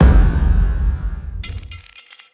impact-6.ogg